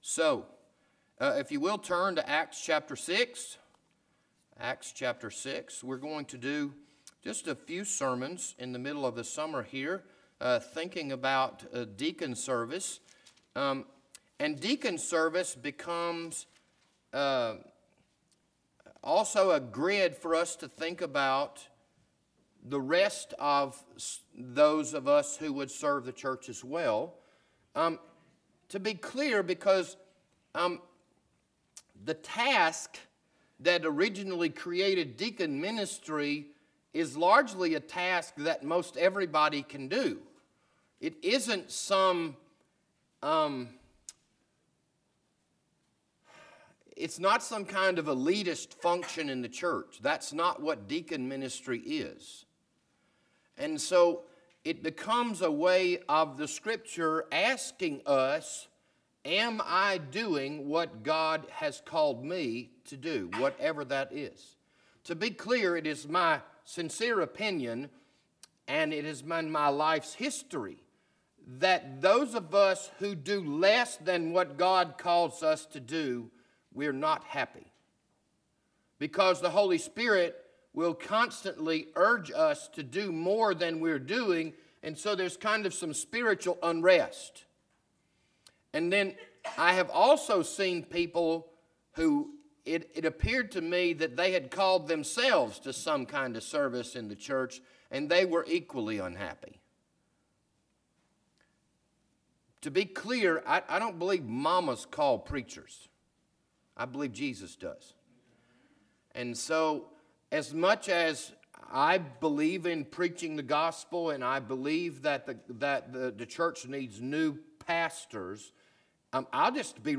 This Sunday morning sermon was recorded on July 21st, 2019.